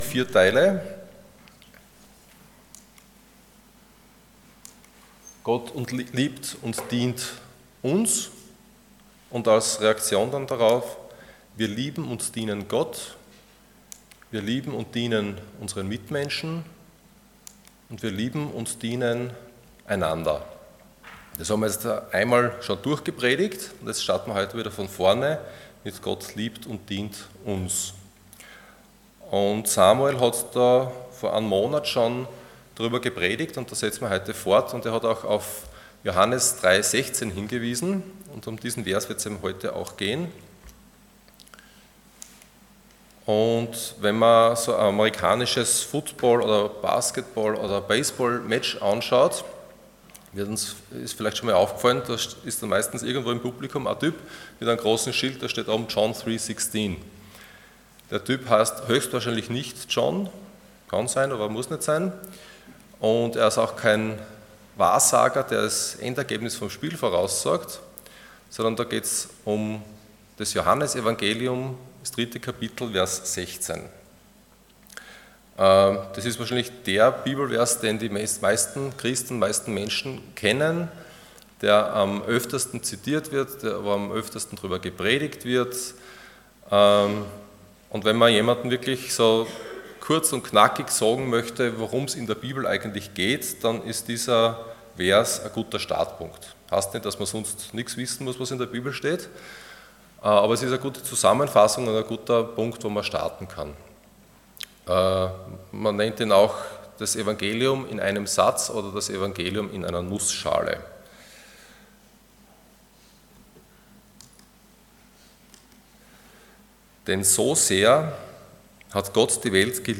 Passage: John 3:16 Dienstart: Sonntag Morgen